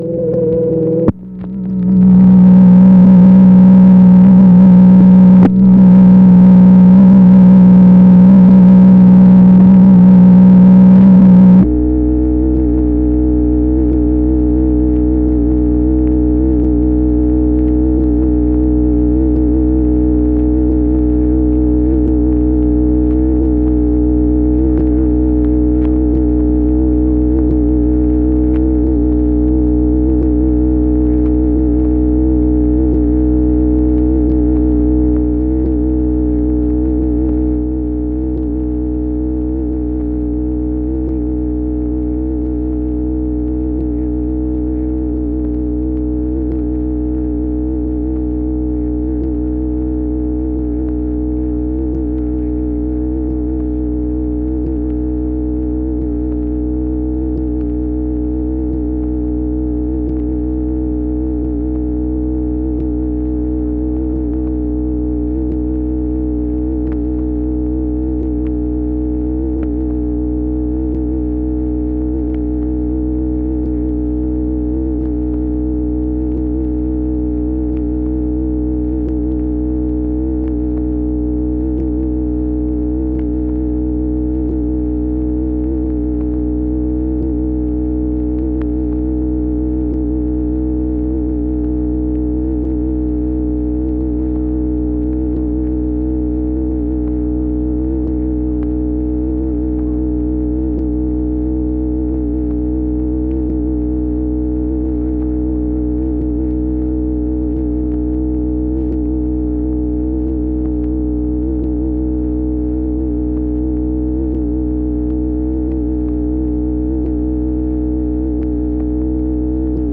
OFFICE NOISE, February 17, 1964
Secret White House Tapes | Lyndon B. Johnson Presidency